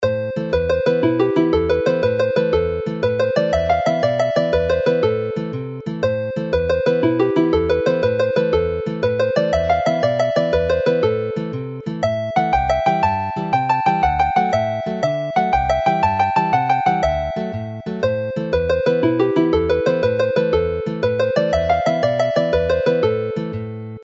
Skip jig based on the melody